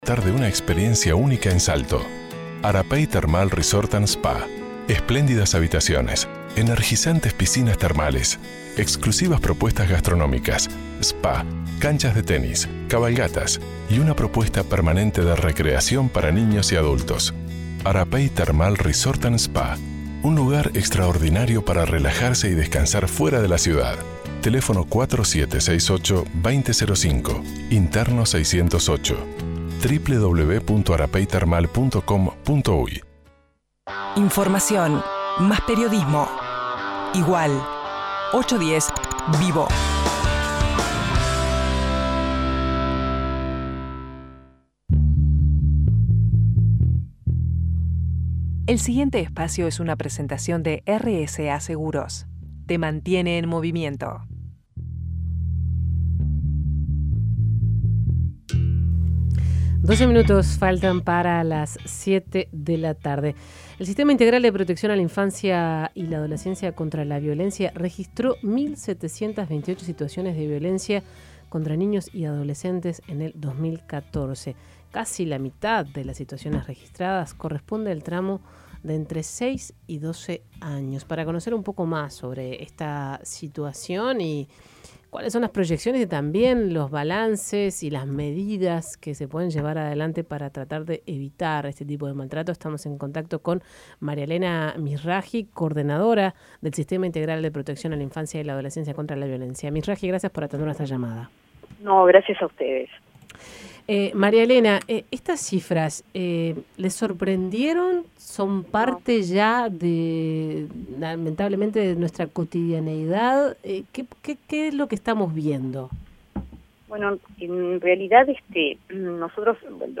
dijo en conversación con 810 Vivo que estas cifras no sorprenden porque ha aumentado la captación y las denuncias de estos casos.